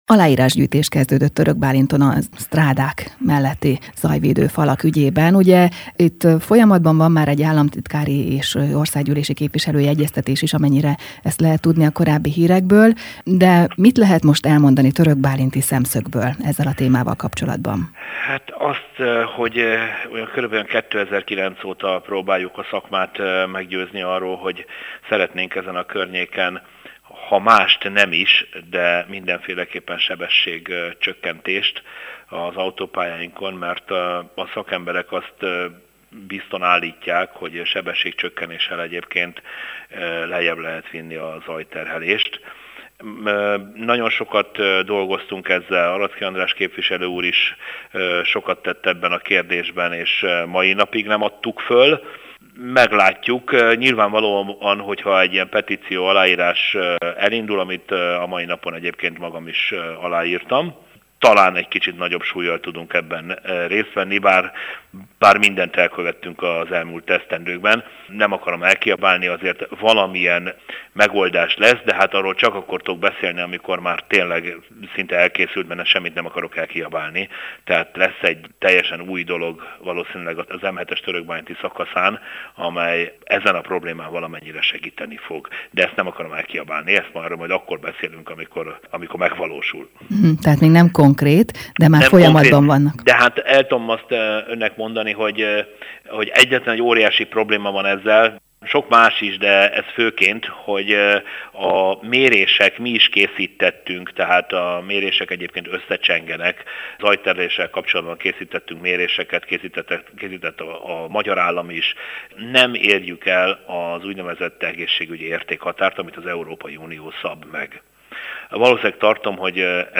A zajszennyezés szintje – a mérések szerint – nem éri el az unió által megszabott egészségügyi értékhatárt – közölte az Érd FM 101.3-on Elek Sándor, de – ,mint mondta – az ott élőknek akkor is hangos.